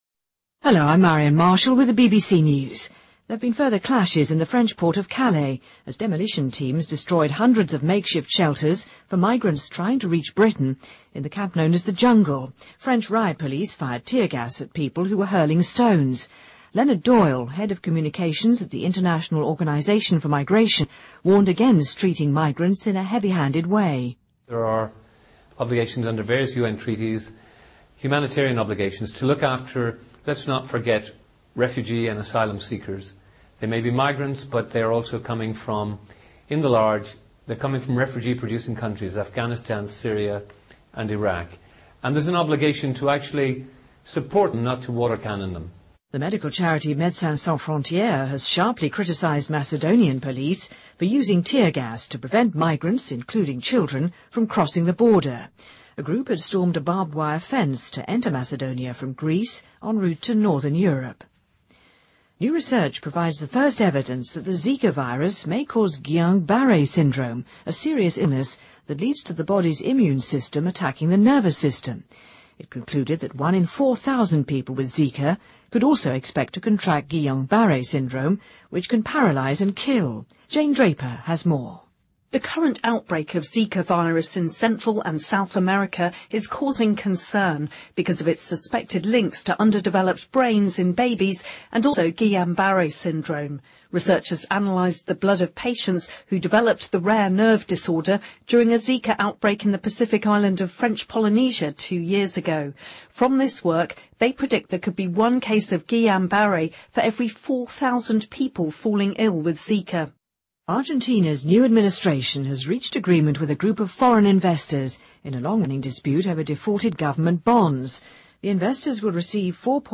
BBC news:研究首次证实寨卡病毒或引发格林-巴利综合征|BBC在线收听